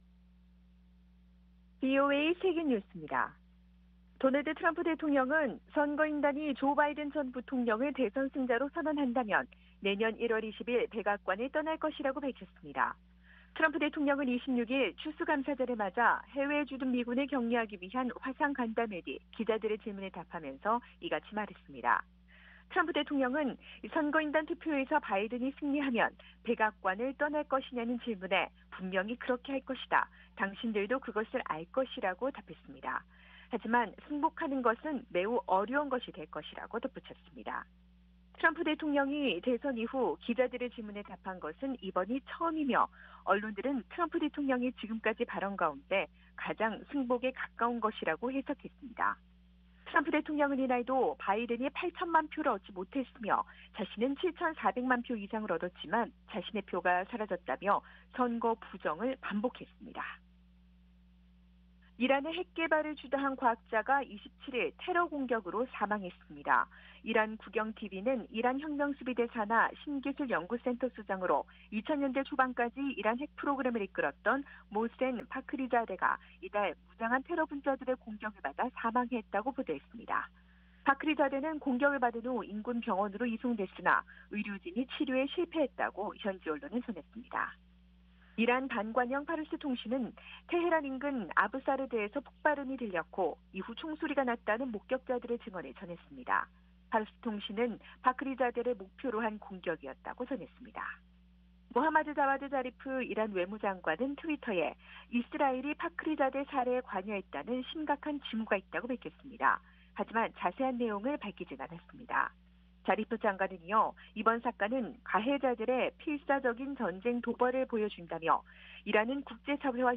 VOA 한국어 아침 뉴스 프로그램 '워싱턴 뉴스 광장' 2020년 11월 28일 방송입니다. 북한은 최근 해외 공관에 미국 대선 결과와 관련한 자극적인 대응을 금지하는 지시를 내렸다고 한국 정보 당국이 밝혔습니다. 한국을 방문한 왕이 중국 외교부장은 한국 외교부 장관과의 회담에서 미국 정권 교체를 앞둔 민감한 시기에 한반도 정세를 함께 안정적으로 관리할 필요가 있다는 데 공감했습니다. 미국이 대북 인도주의 지원을 위한 제재 면제 신청을 더 신속하게 승인하고 면제 기간도 늘릴 것을 유엔에 제안했습니다.